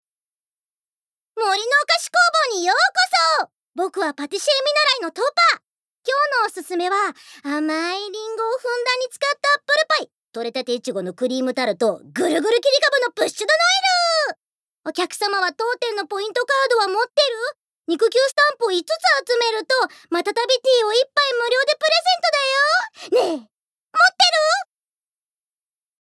ボイスサンプル
セリフ７